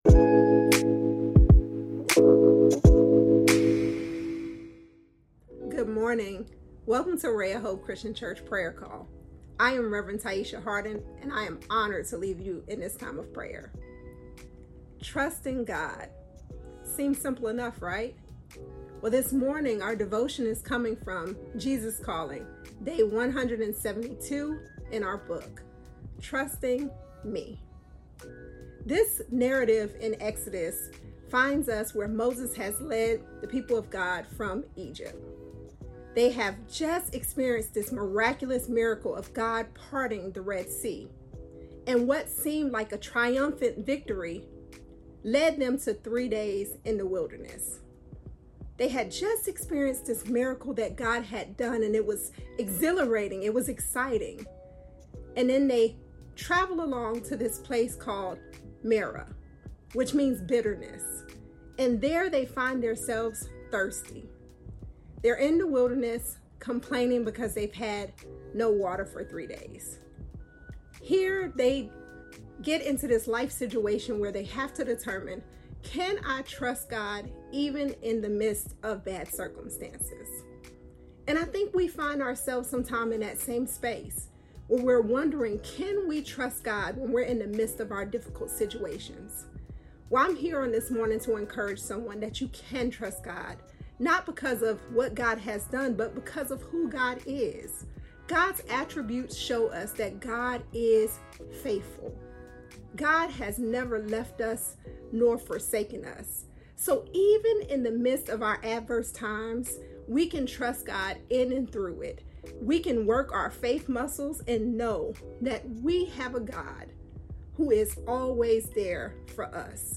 Prayer Call